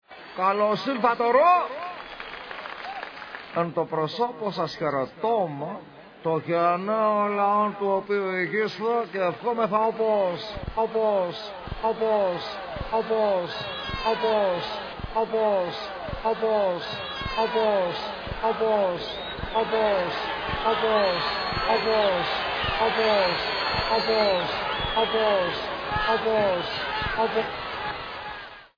Ηχητική μπάντα παράστασης
sound 28'', track 14, ηχητικά εφέ (εμβατήριο, ομιλία, μουσική και ζητοκραυγές)